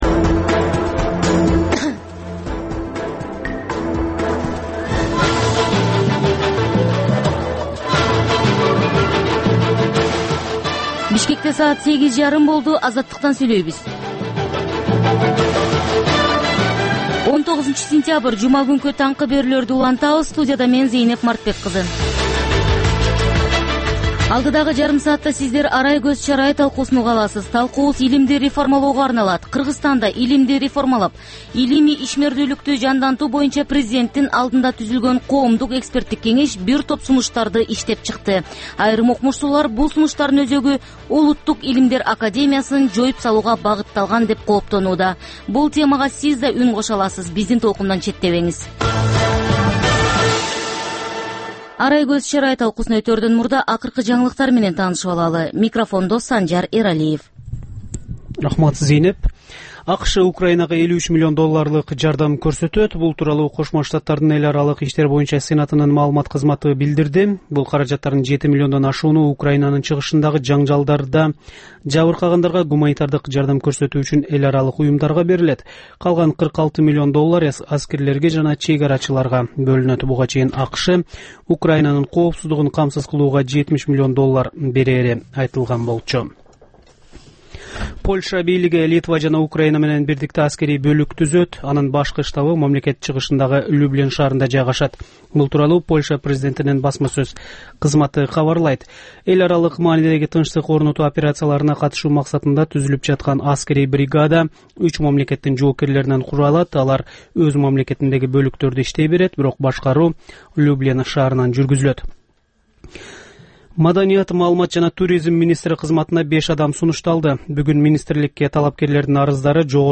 Бул таңкы үналгы берүүнүн 30 мүнөттүк кайталоосу жергиликтүү жана эл аралык кабарлар, ар кыл орчун окуялар тууралуу репортаж, маек, күндөлүк басма сөзгө баяндама, «Арай көз чарай» түрмөгүнүн алкагындагы тегерек үстөл баарлашуусу, талкуу, аналитикалык баян, сереп жана башка берүүлөрдөн турат. "Азаттык үналгысынын" бул берүүсү Бишкек убакыты боюнча саат 08:30дан 09:00га чейин обого чыгарылат.